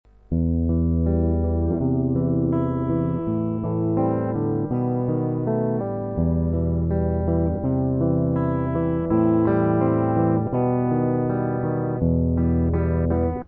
Проигрыш как-нибудь на свой манер, например так: